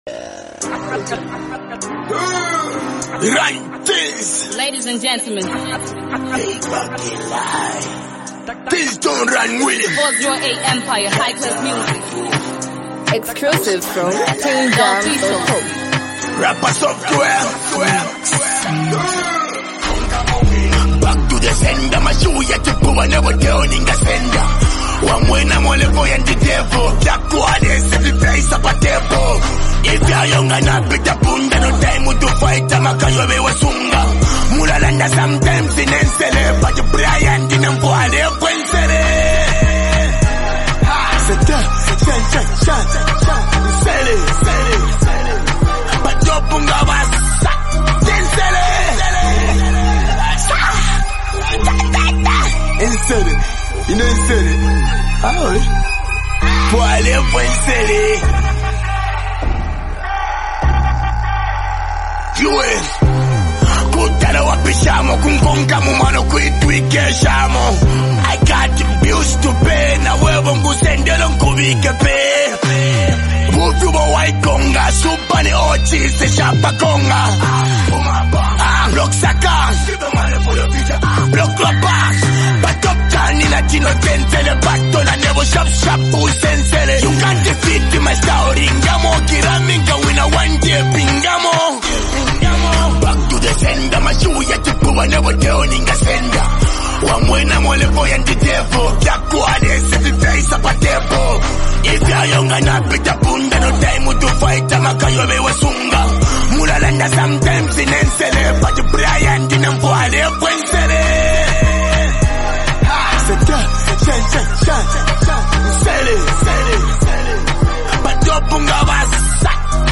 bold and energetic track
fast-paced flow